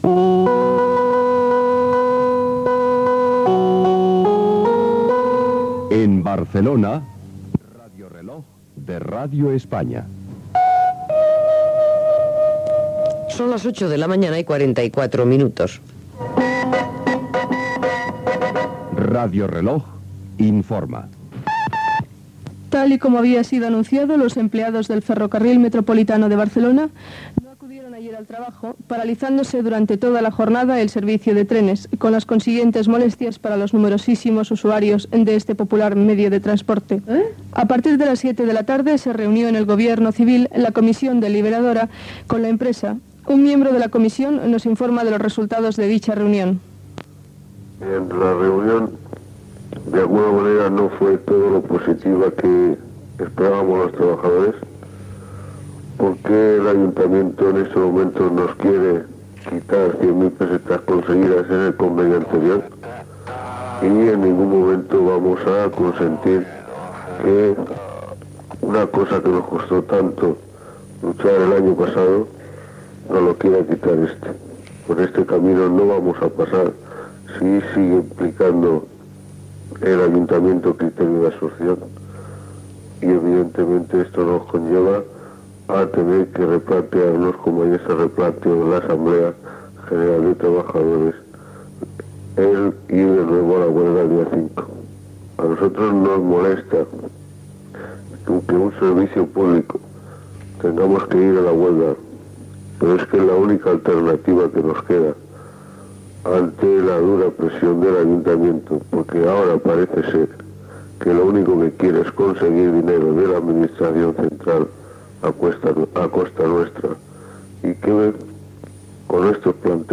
Identificació, hora, informació sobre la vaga al Ferrocarril Metropolità de Barcelona (Metro), indicatiu de l'emissora, hora
Informatiu